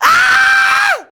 SCREAM 1.wav